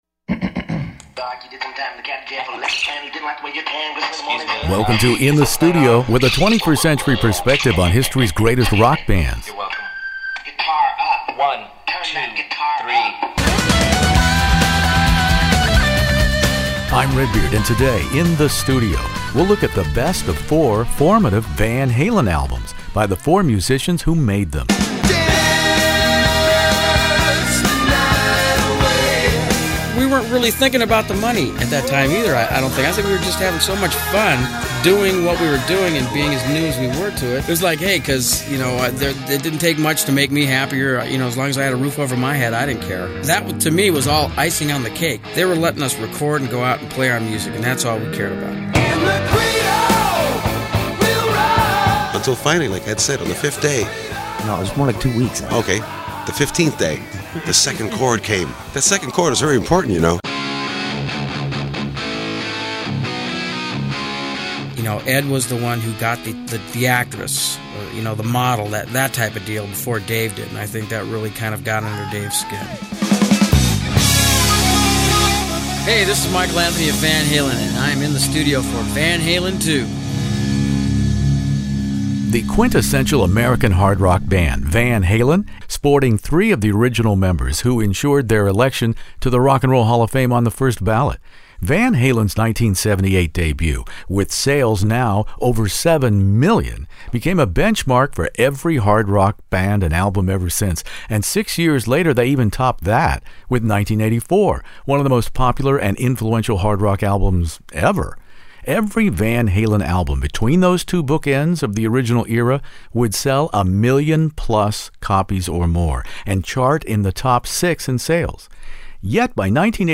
Van Halen II interview In the Studio with Eddie & Alex Van Halen, David Lee Roth, Michael Anthony